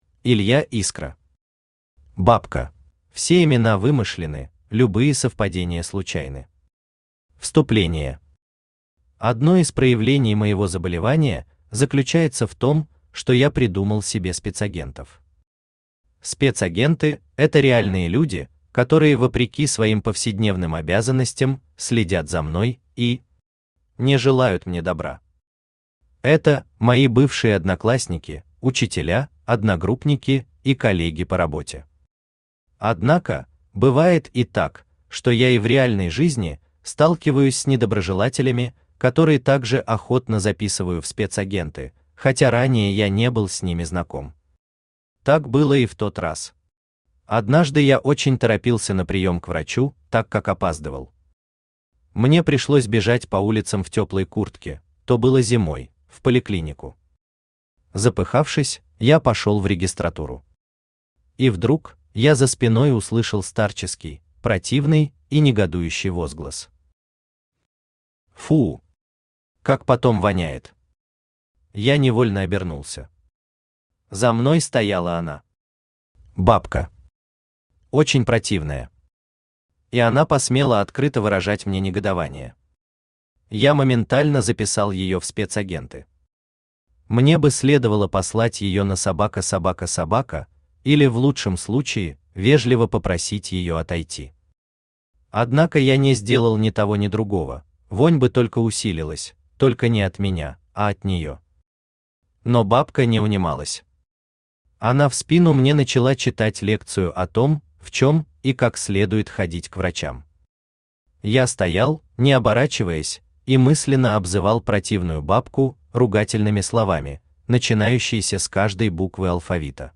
Аудиокнига Бабка | Библиотека аудиокниг
Aудиокнига Бабка Автор Илья Искра Читает аудиокнигу Авточтец ЛитРес.